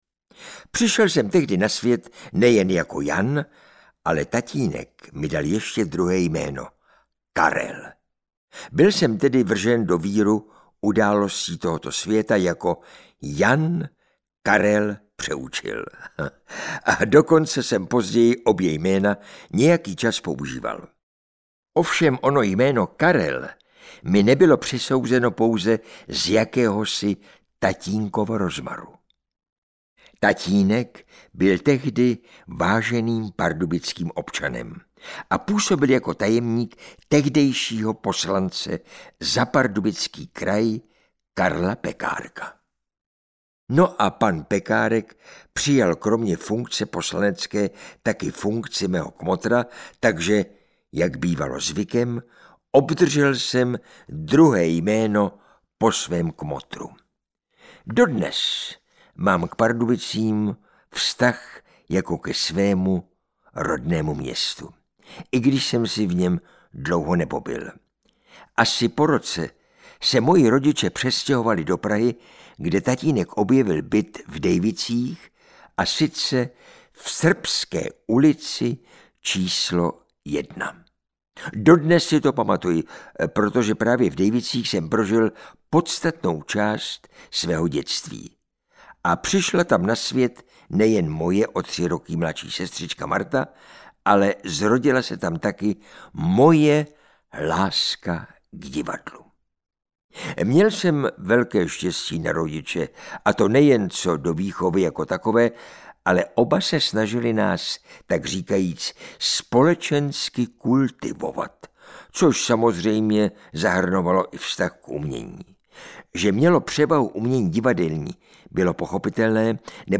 Audiokniha
Abychom zpřístupnili hercovo vzpomínání i těm, kteří si oblíbili knihy v jejich zvukové formě, rozhodli jsme se přinést vám jej nyní ve formě audioknihy, abyste si mohli vychutnat i nezaměnitelný hlasový projev této výrazné herecké osobnosti.